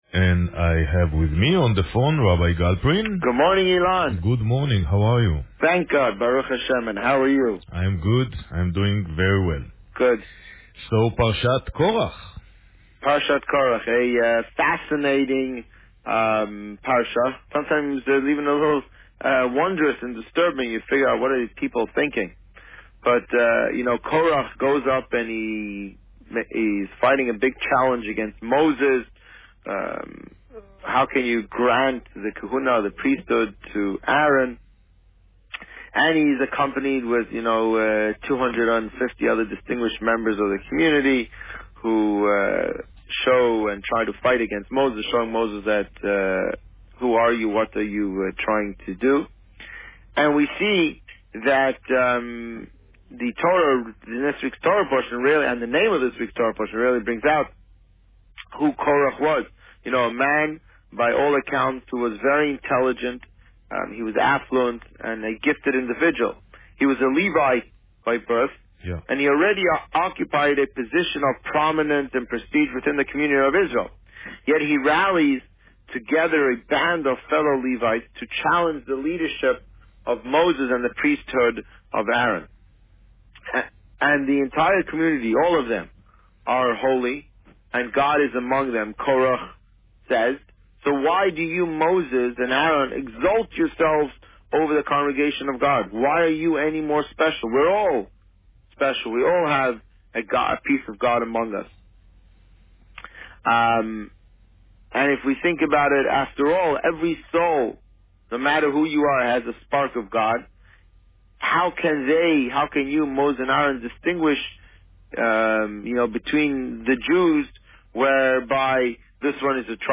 On June 18, 2015, the Rabbi spoke about Parsha Korach. Listen to the interview here.